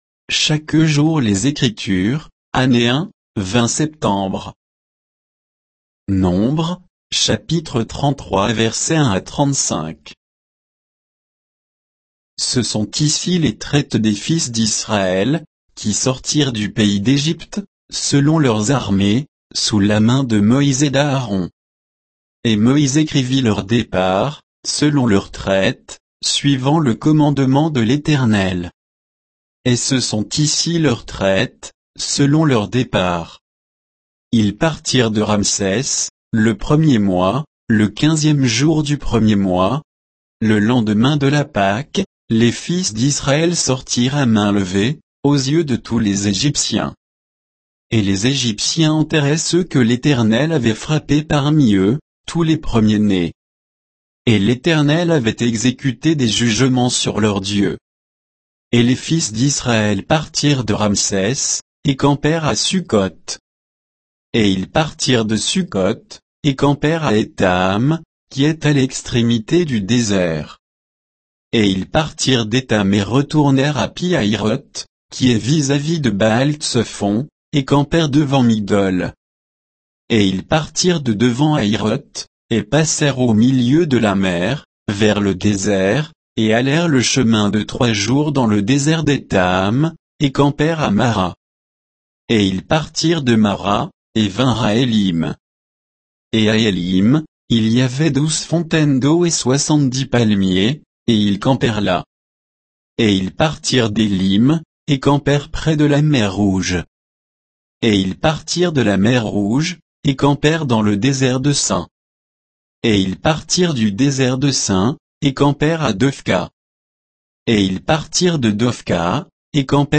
Méditation quoditienne de Chaque jour les Écritures sur Nombres 33